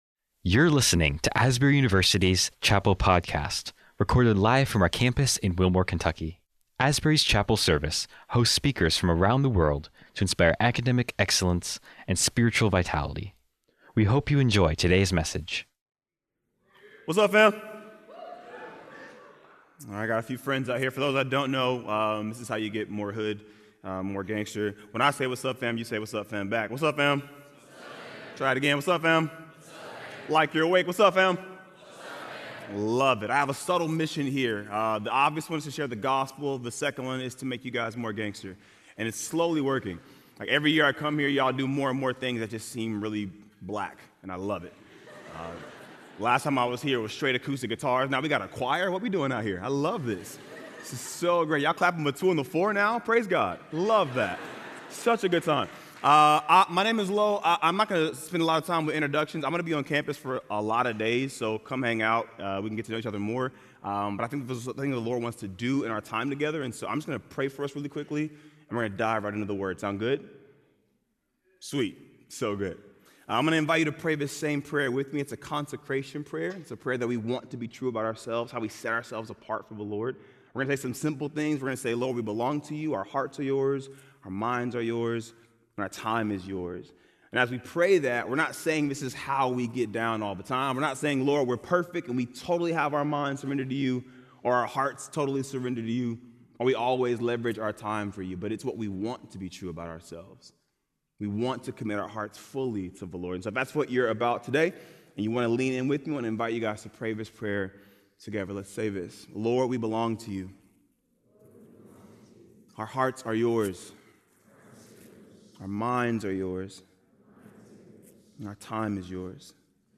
1 Learning To Ai Episode 2 - An Interview with ChatGPT 30:50